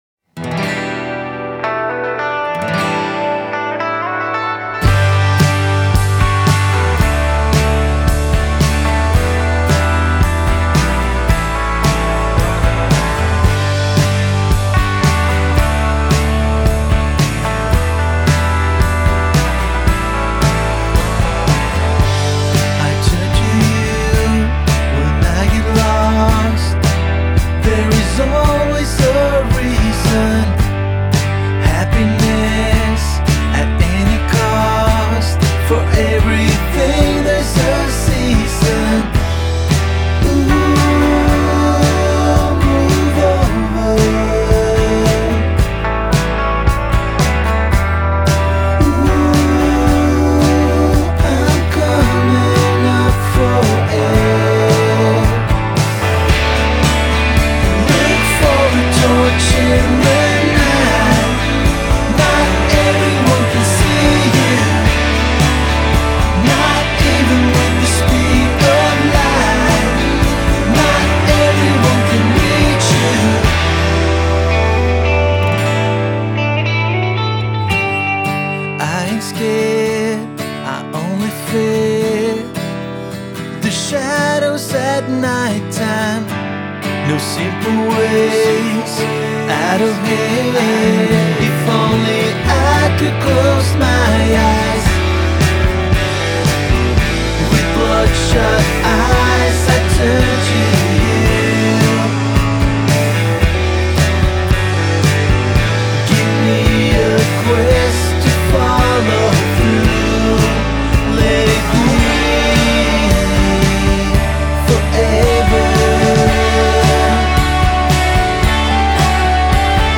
midtempo, Teenage Fanclub-ish elegance